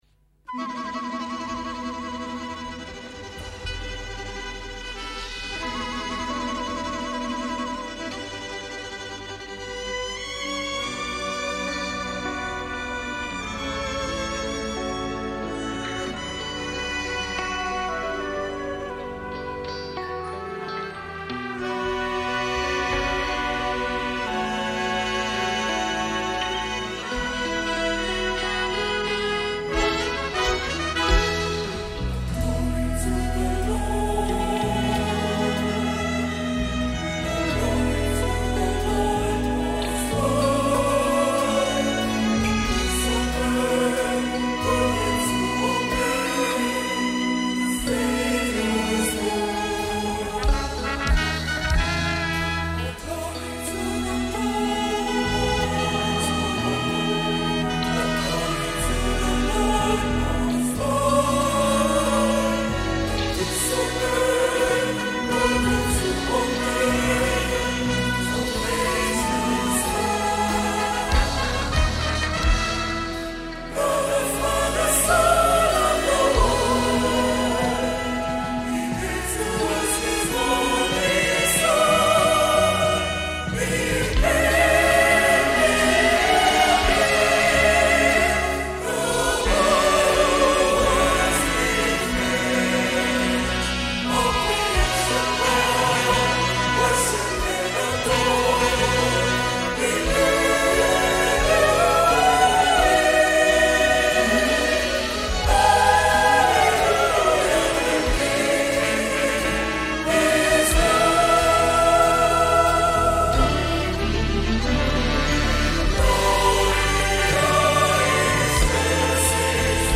MORE CAROL SONGS